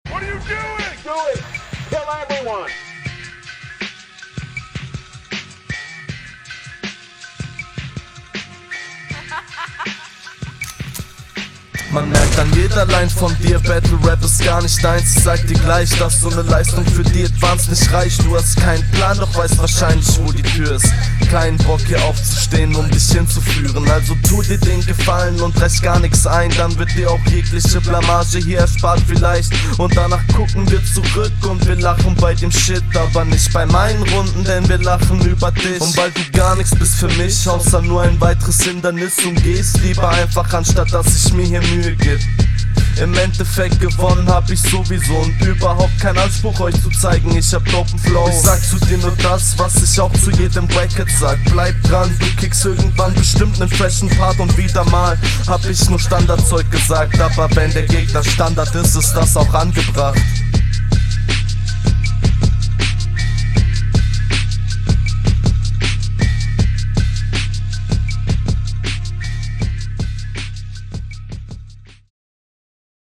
Darke Atmo, gefällt. Stimmeinsatz sitzt perfekt, Variation vorhanden, alles sicher, wenn auch nicht krass spektakulär.